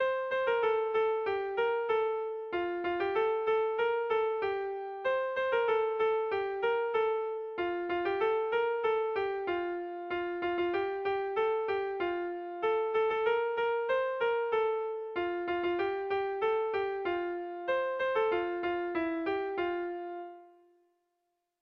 Kontakizunezkoa
Zortzi puntuko berdina, 8 silabaz
ABABDEDF